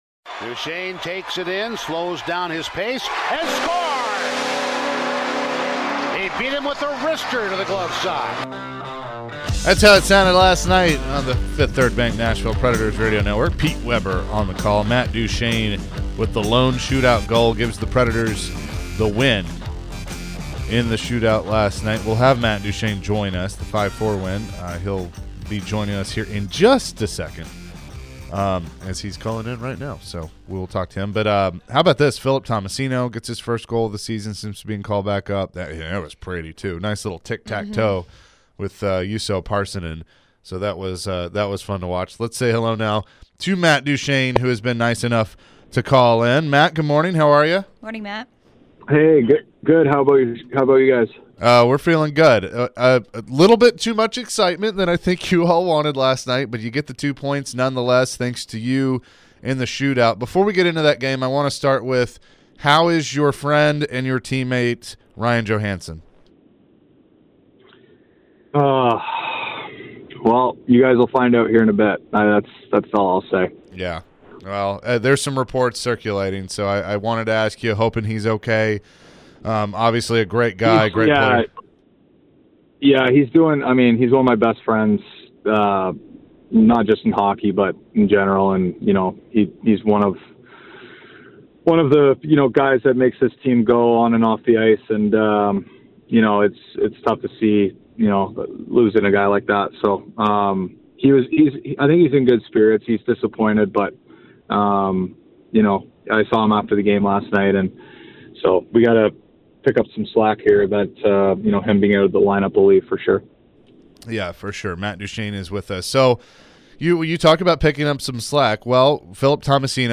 Matt Duchene Interview (2-22-23)